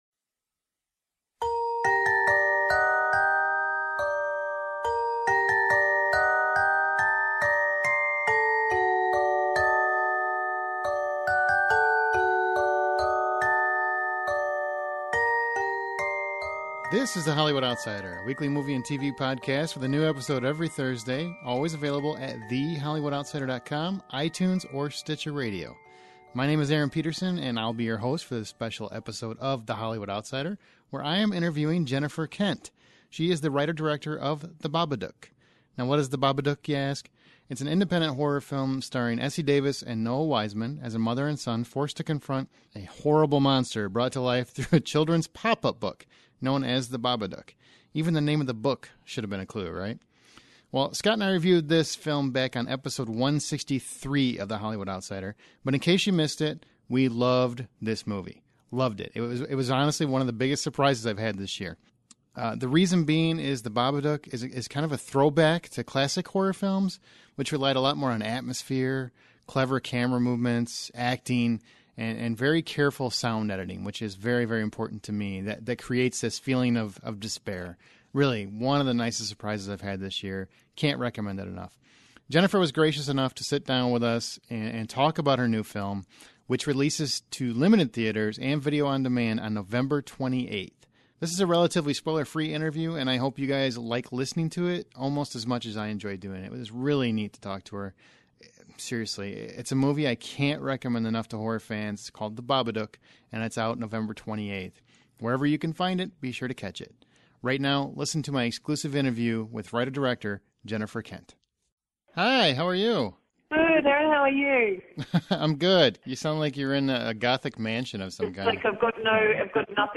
In our exclusive interivew with Jennifer, she discusses the film, where the idea even came from, sound editing in a film like this, and what it's like to create a horror film. An insightful interview with a director to watch, here is our interview with The Babadook's Jennifer Kent!